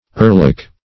Search Result for " earlock" : The Collaborative International Dictionary of English v.0.48: Earlock \Ear"lock`\, n. [AS. e['a]r-locca.] A lock or curl of hair near the ear; a lovelock.